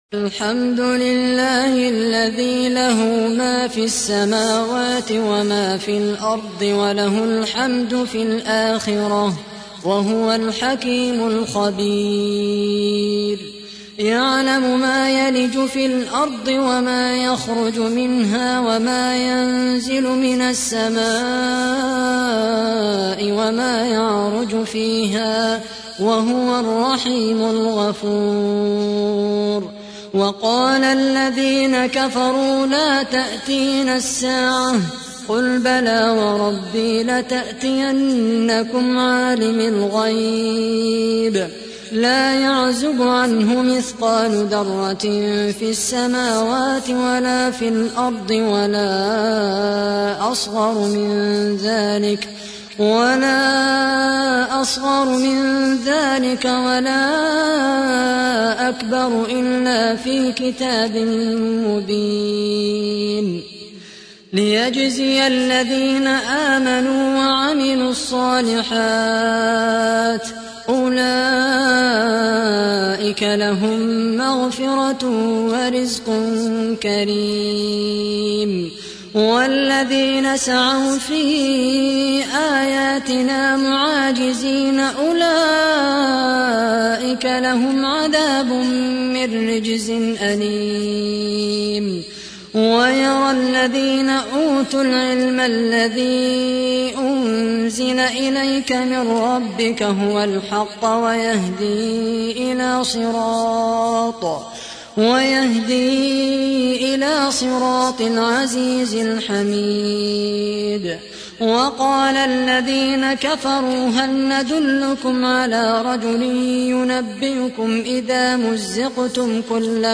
تحميل : 34. سورة سبأ / القارئ خالد القحطاني / القرآن الكريم / موقع يا حسين